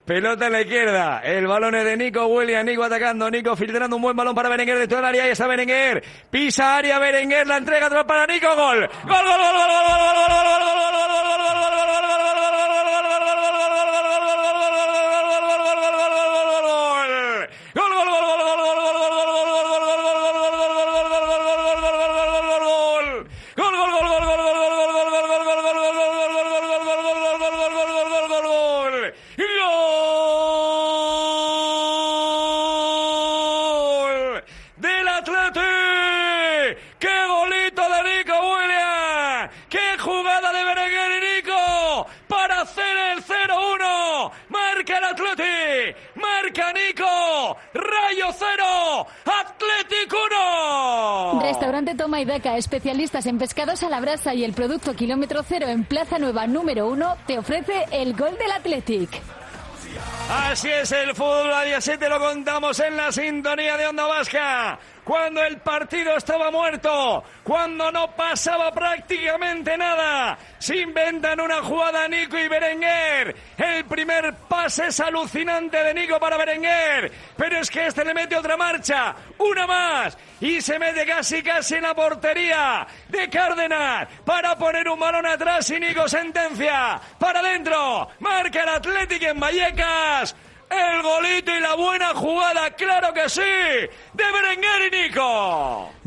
Partidos Athletic